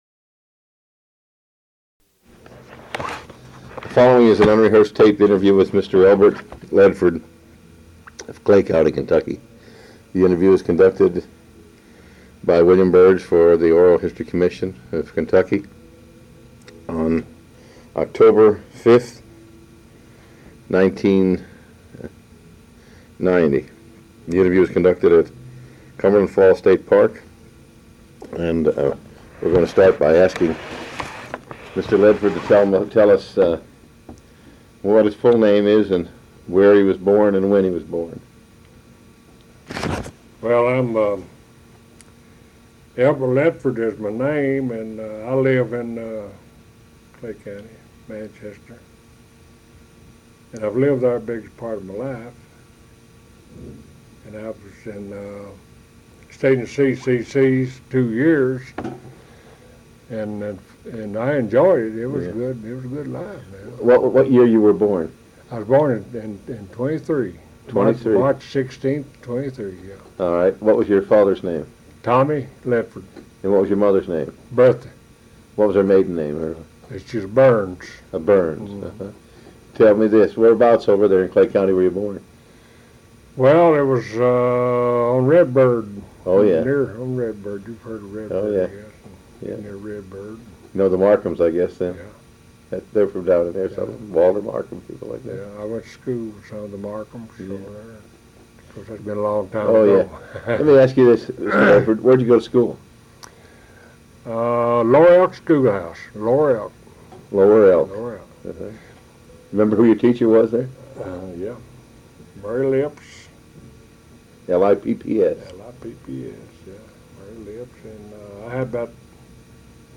C.C.C. (Civilian Conservation Corps) Oral History Project